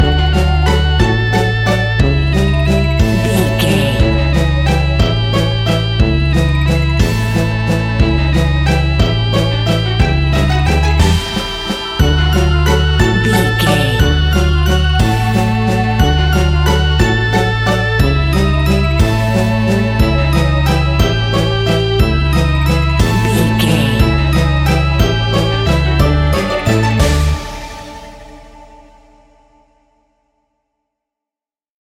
Aeolian/Minor
ominous
dark
eerie
playful
strings
synthesiser
percussion
creepy
spooky
horror music